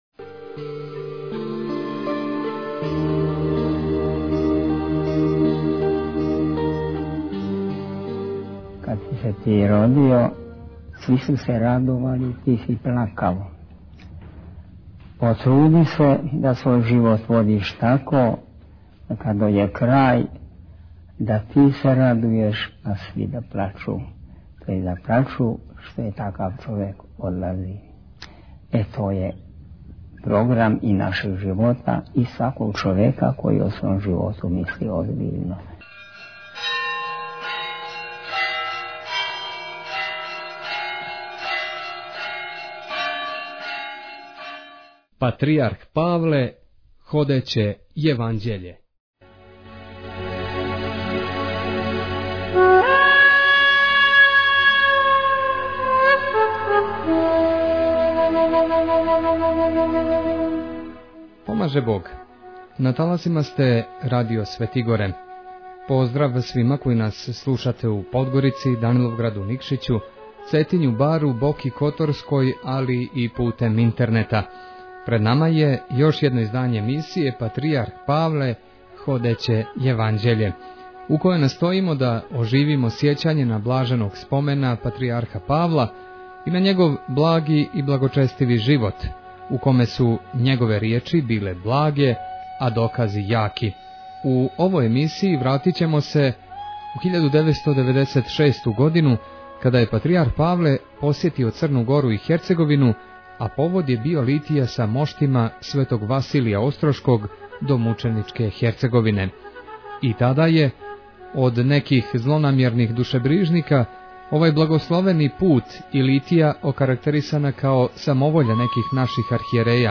Тим поводом на таласима радија Светигоре сваке сриједе у 20 и 30 емитоваћемо емисију посвећену Патријарху Павлу. У првом дијелу ове емисије чућете бесједе патријарха Павла изговорене 1996. године у манастиру Острогу и манастиру Тврдошу када је организована Литија са моштима Светог Василија Острошког